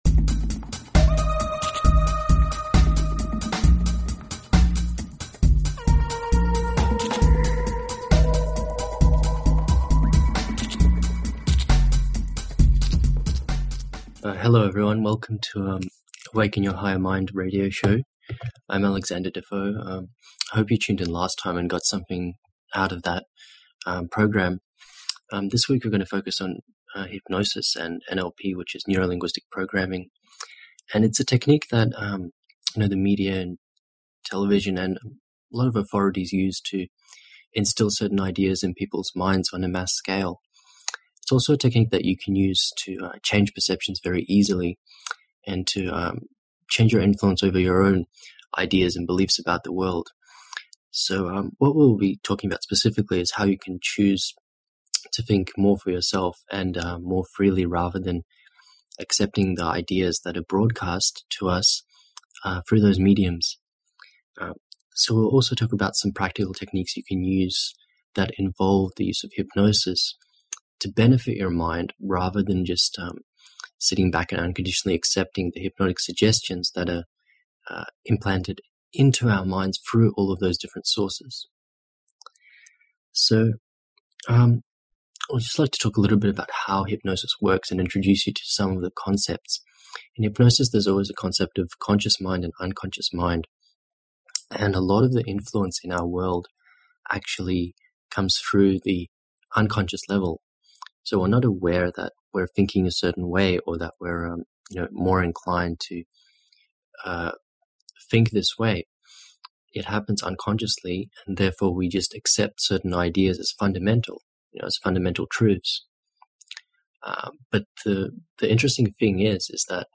Talk Show Episode, Audio Podcast, Awaken_Your_Higher_Mind and Courtesy of BBS Radio on , show guests , about , categorized as